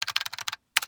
MiniKeyboard.wav